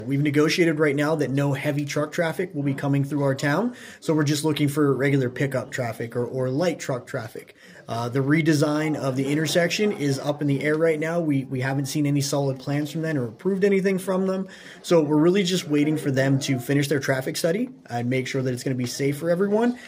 At Tuesday’s meeting, Homer City Borough Council issued a statement on a proposed redevelopment connected with Homer City Redevelopment.
Homer City Borough Mayor Kyle Cobaugh said that they have the community safety in mind and are working with HCR and PennDOT on safety issues, and nothing is set in stone yet.